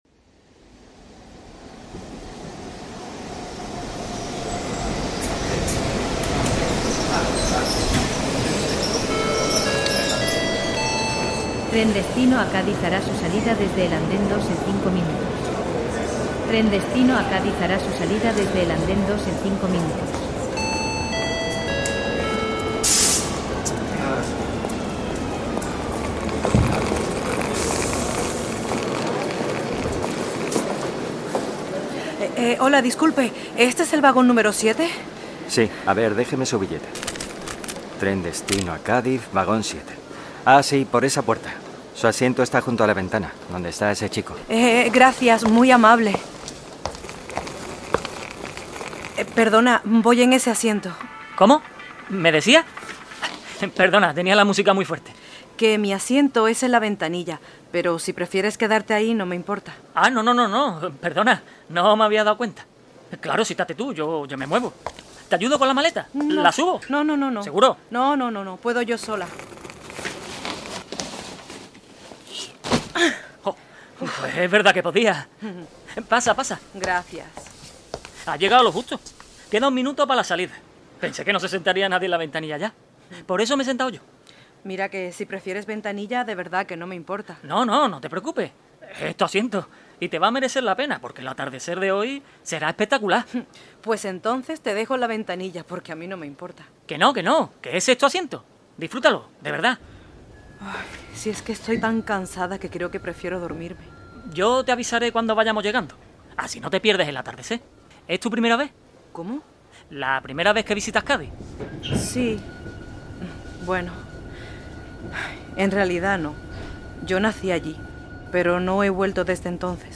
Con la banda sonora original